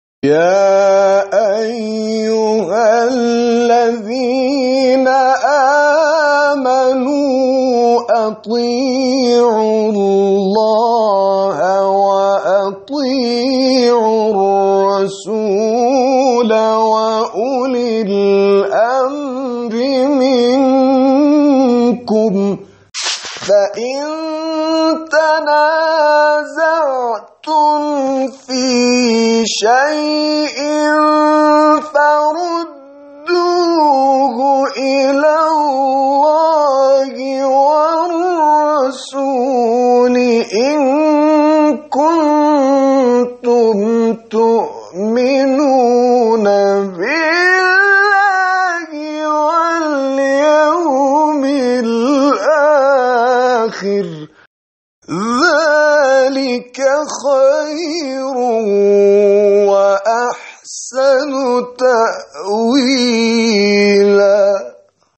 تلاوت نفرات برتر مسابقه از دید داوران:
تلاوت